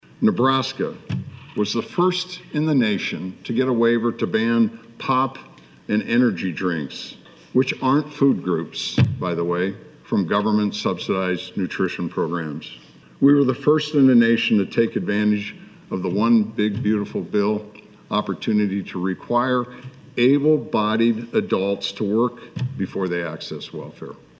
NEBRASKA GOVERNOR JIM PILLEN DELIVERED HIS STATE OF THE STATE ADDRESS THURSDAY IN LINCOLN, SAYING THE STATE HAS MADE TREMENDOUS PROGRESS AND HE CITED PARTNERSHIPS WITH FEDERAL AGENCIES AND PROGRAMS: